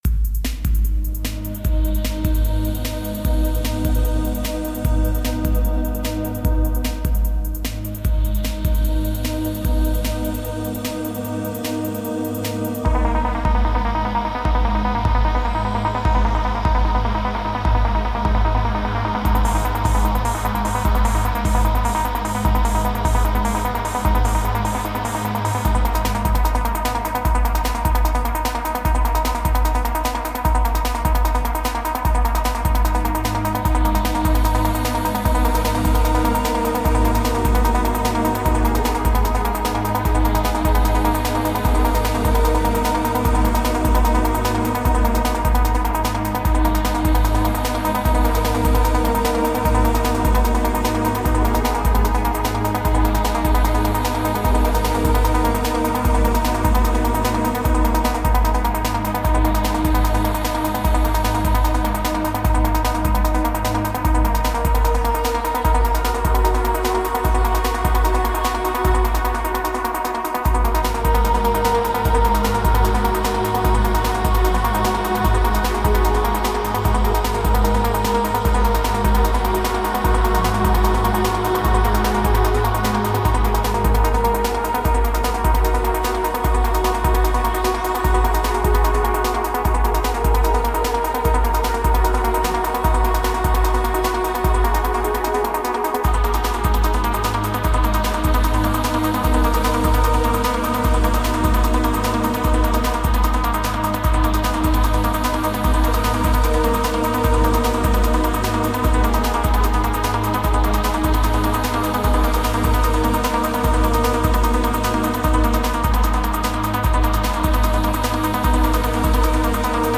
Sublime and ethereal electronic music.